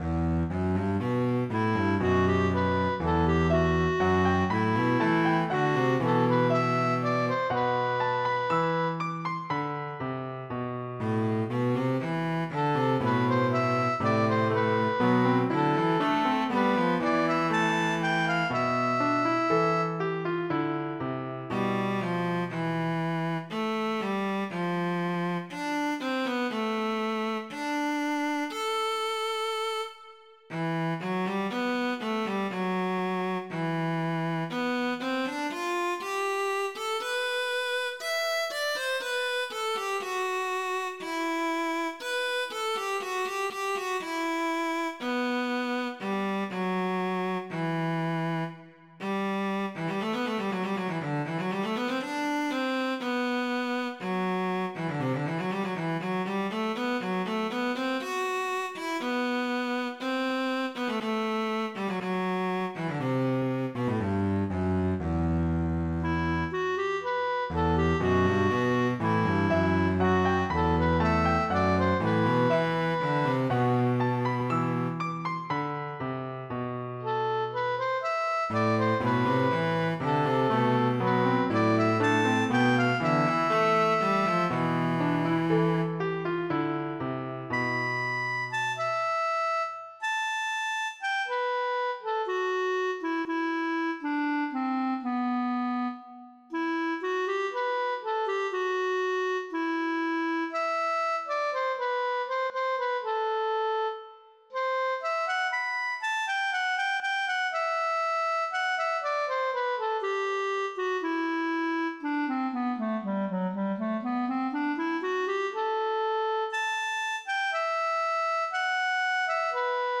mp3-Aufnahme: mit midi Instrument